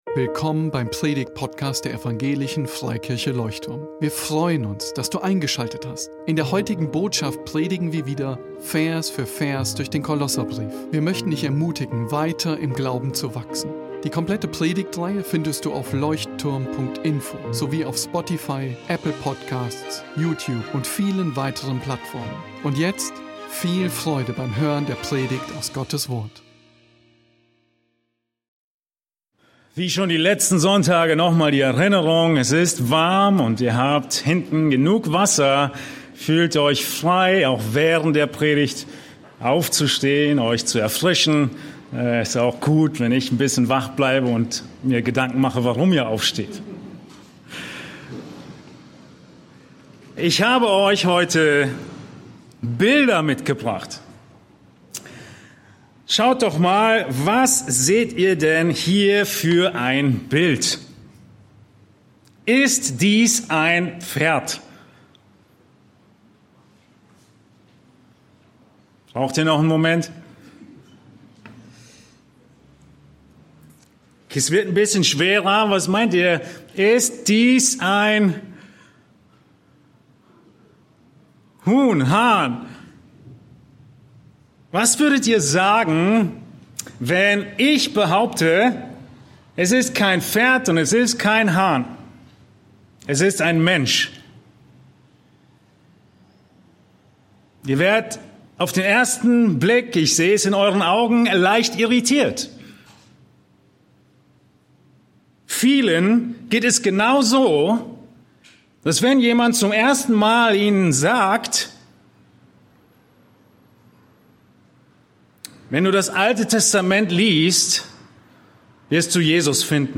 In dieser Predigt aus der Kolosserbrief-Serie geht es um Gesetzlichkeit.